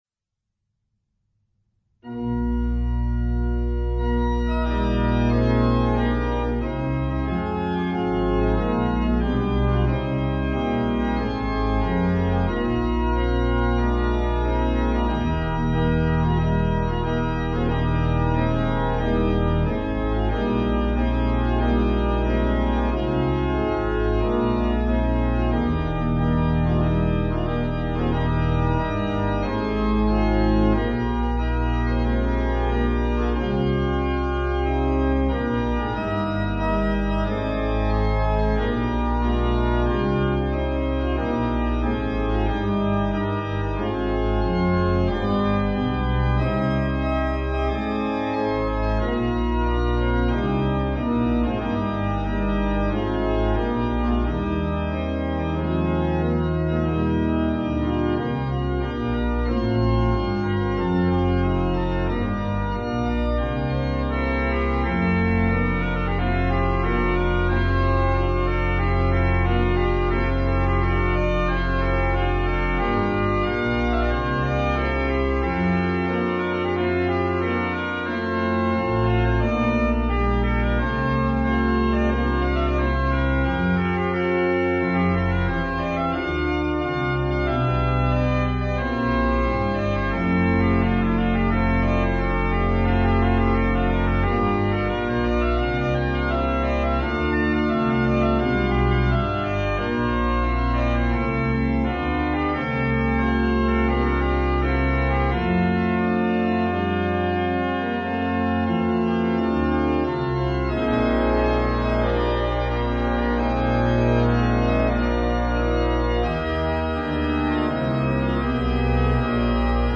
Postludes